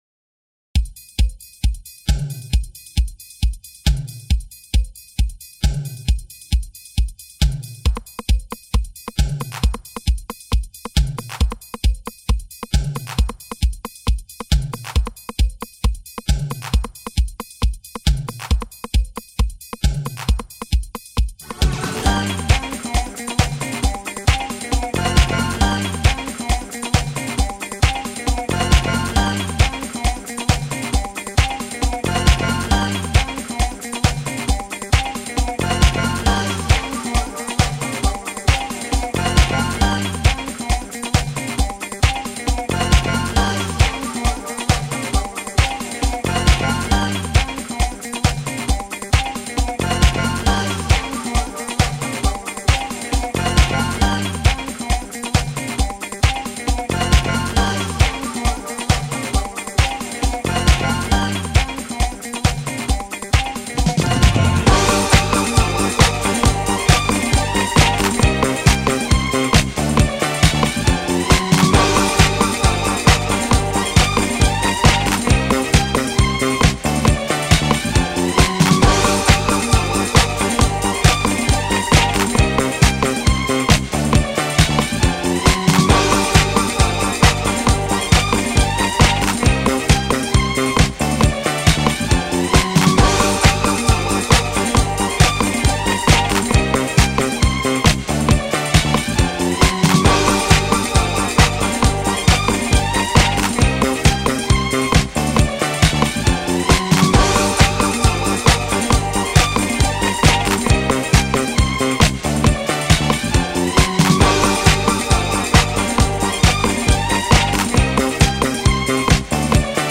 disco track